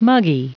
Prononciation du mot muggy en anglais (fichier audio)
Prononciation du mot : muggy